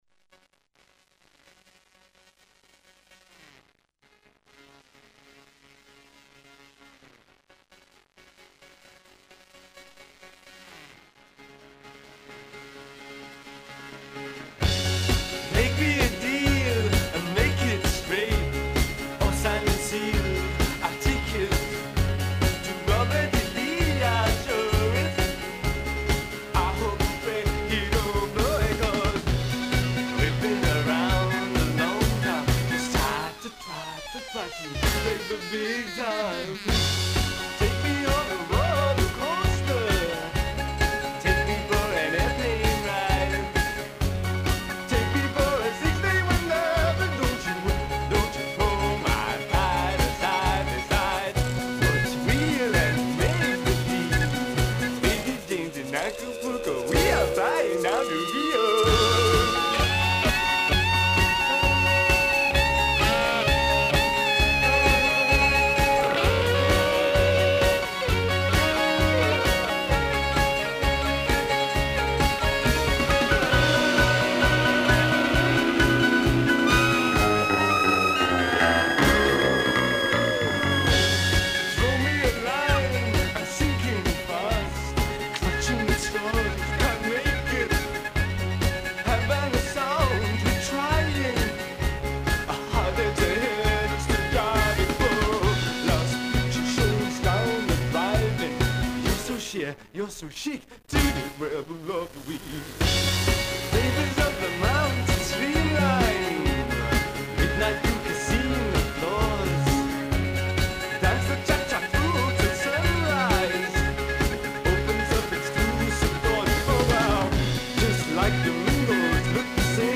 blues rock
rock sudiste